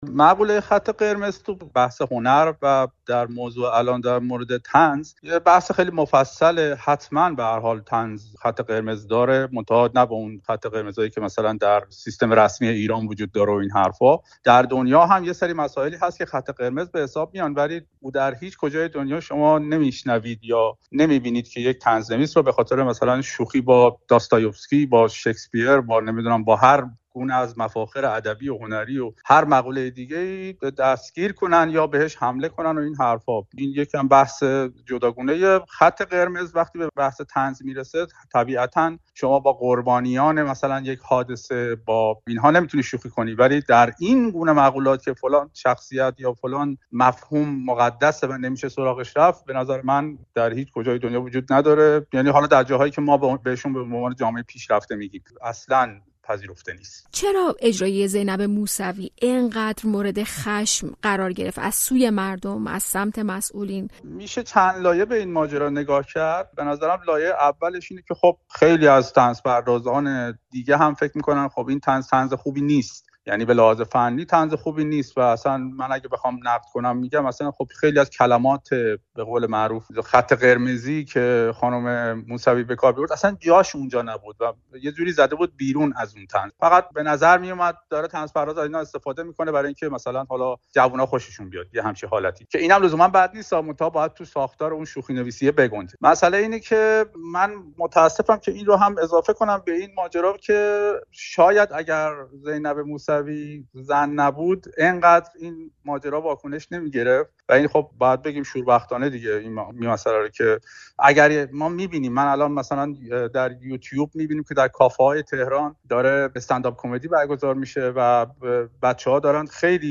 گفت‌وگو کرده‌ایم